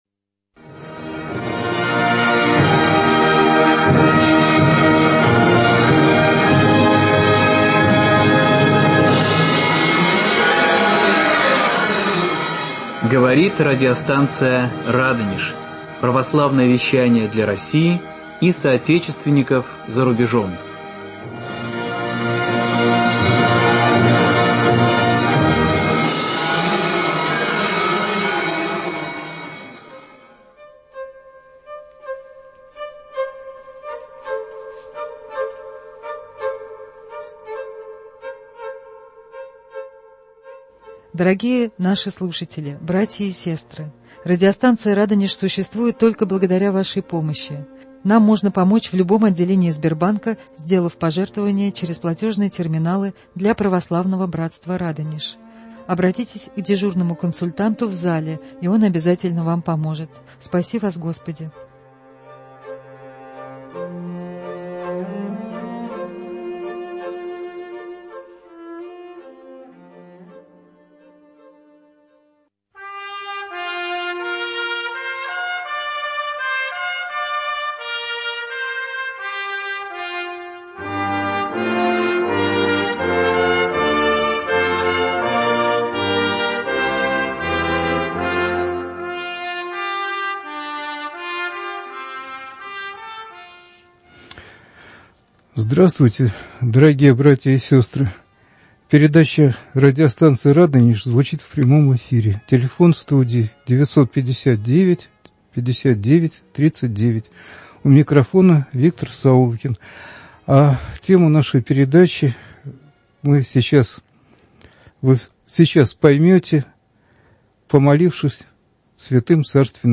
рассказывает в эфире радиостанции "Радонеж"